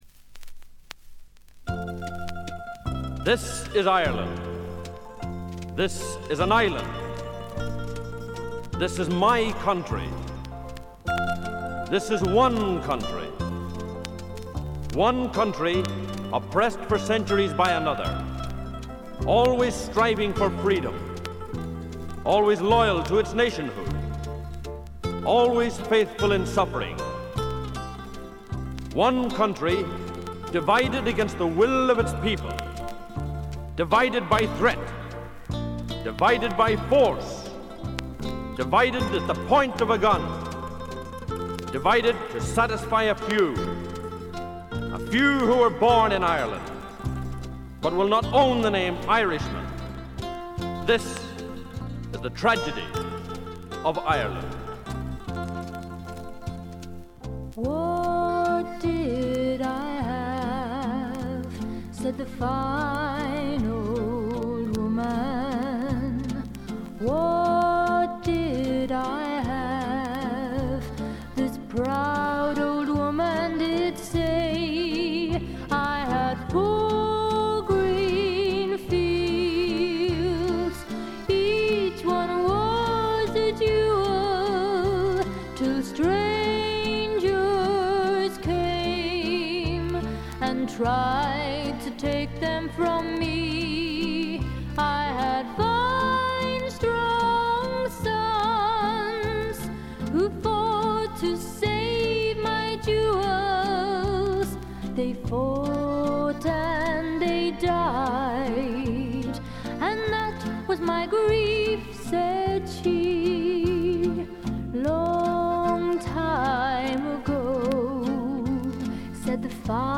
バックグラウンドノイズ、チリプチ、プツ音等多め大きめ。ところどころで周回ノイズもあり。
あとは鼓笛隊的な音が好きなマニアかな？？
試聴曲は現品からの取り込み音源です。
Recorded At - Trend International